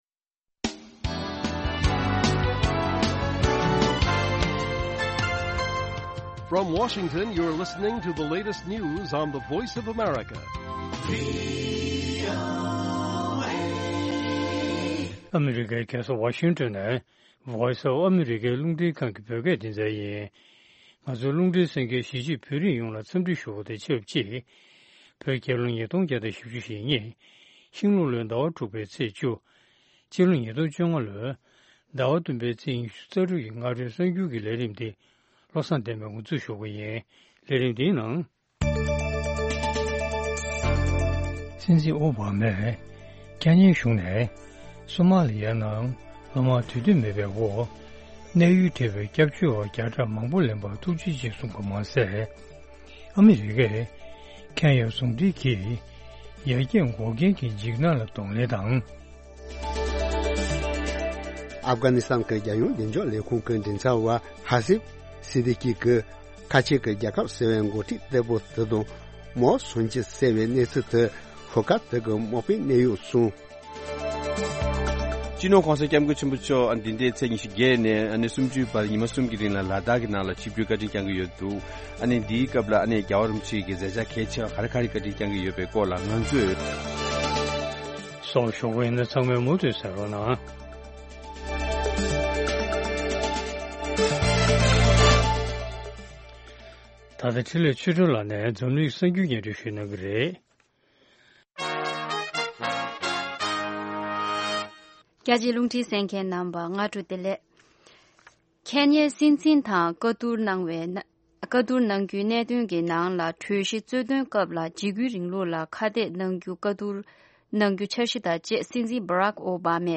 Morning News Morning News Broadcast daily at 08:00 AM Tibet time, the Morning show is a lively mix of regional and world news, correspondent reports, and interviews with various newsmakers and on location informants.